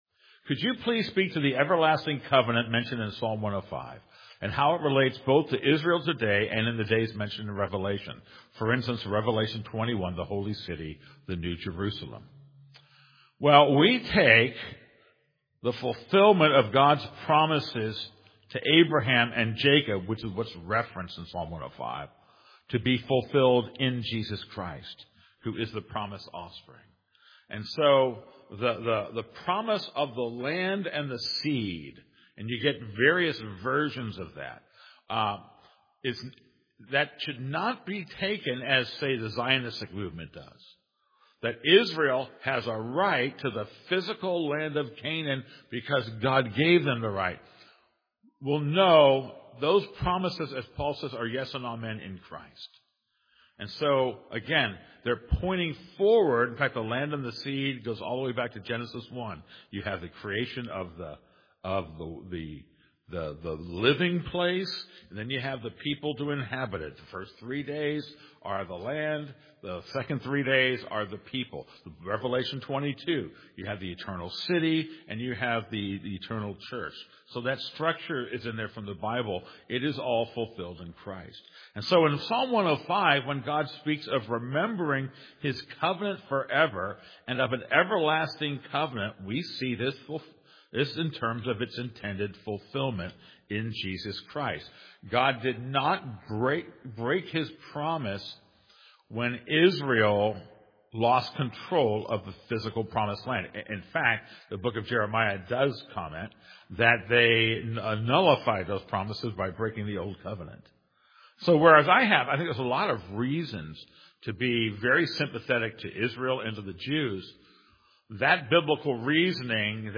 Question & Answer